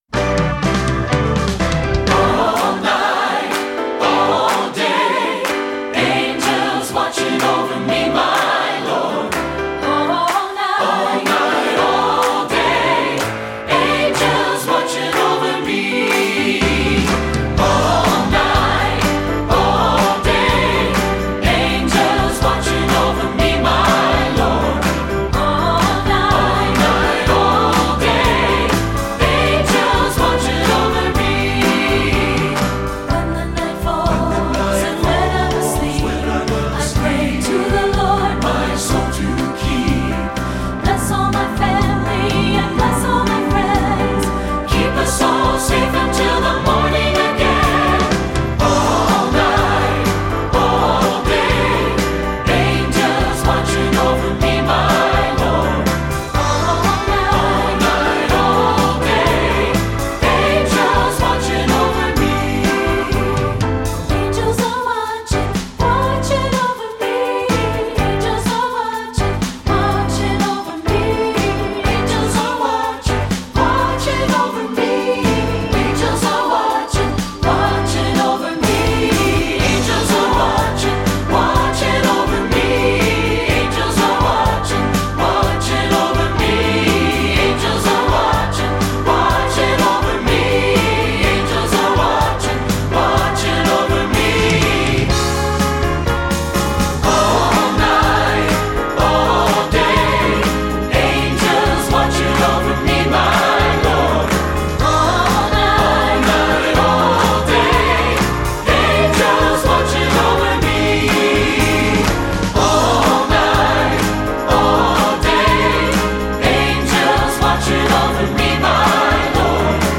Composer: Spiritual
Voicing: SATB and Piano